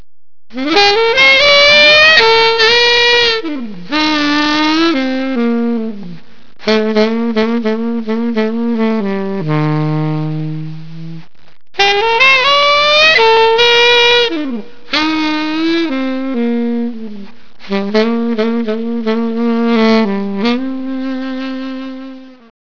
Blues saxophone